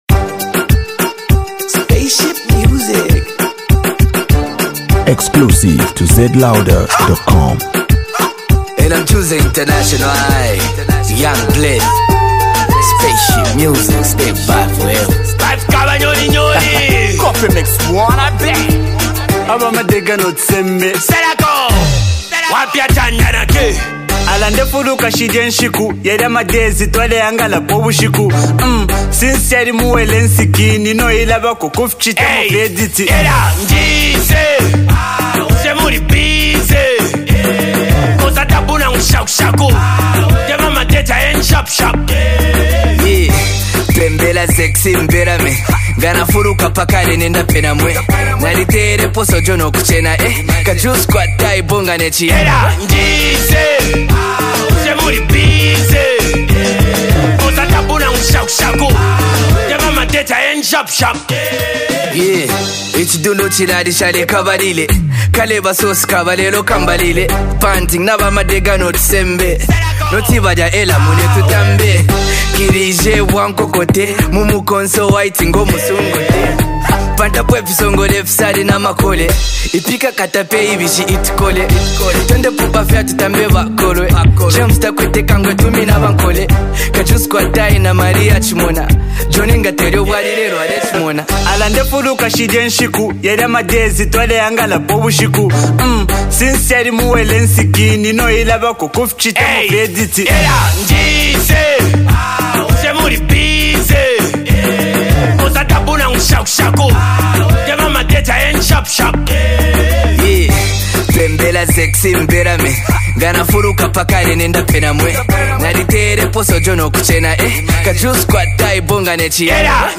Heavy Club Banger